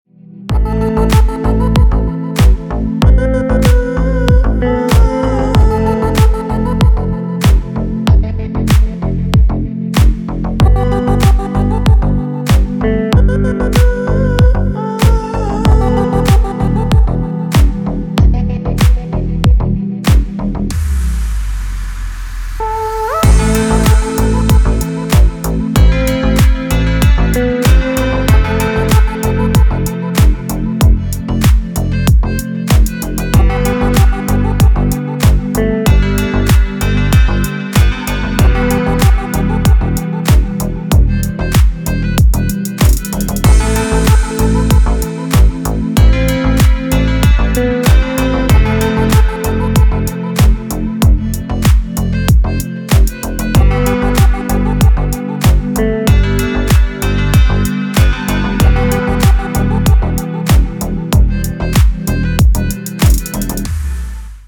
Рингтон
мелодичные без слов